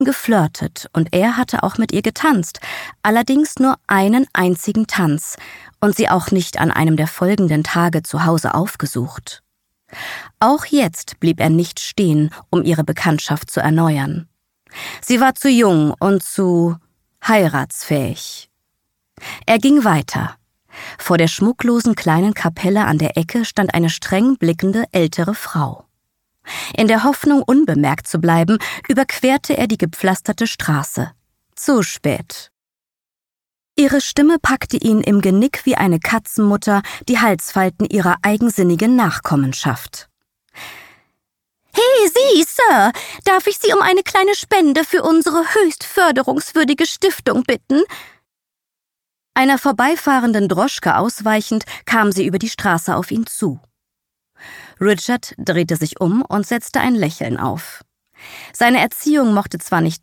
(MP3-Hörbuch - Download)